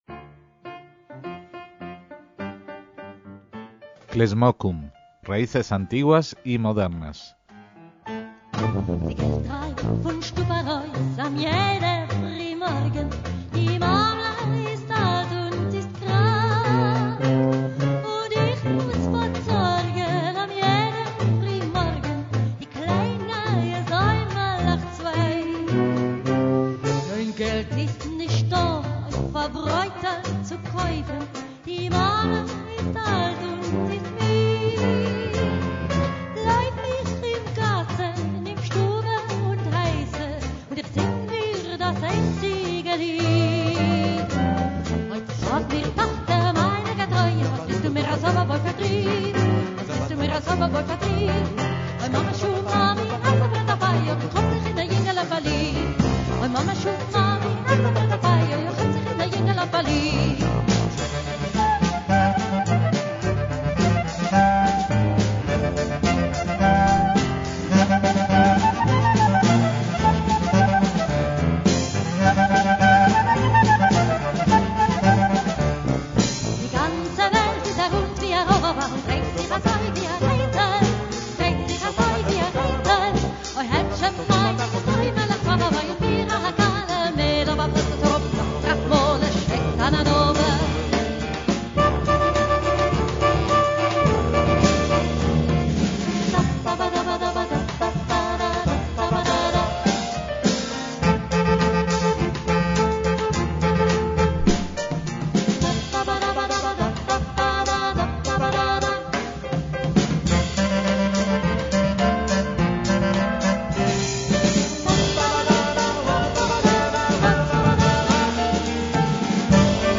MÚSICA ÍDISH
clarinete
clarinetes y saxo
batería
piano
tuba
fusión pionera de klezmer, groove balcánico y jazz